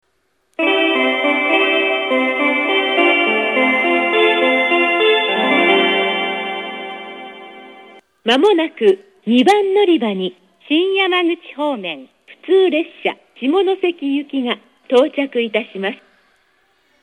自動放送の声優は阪和線と同様ですが、メロディは当駅独自のものです。
予告/下関 下関行きしか録っておりません（ごめんなさい）。
hofu2_b_shimonoseki.mp3